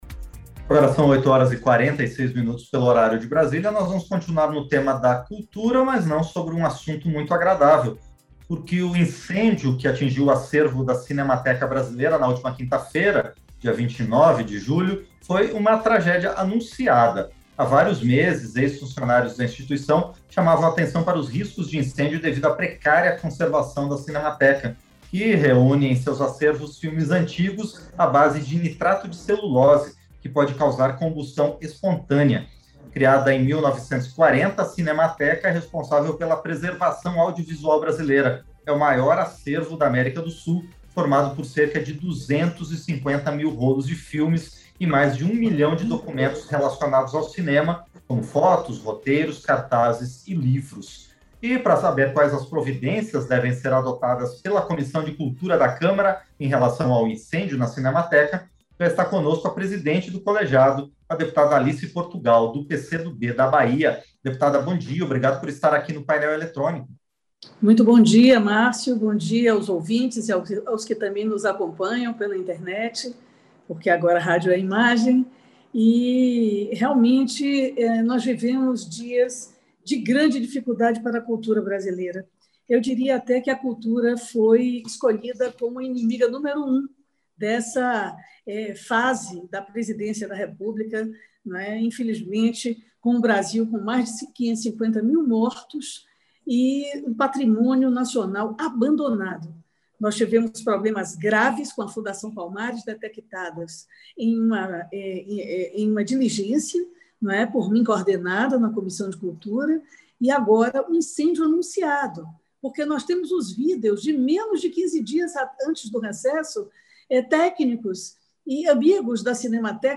Entrevista - Dep. Alice Portugal (PCdoB-BA)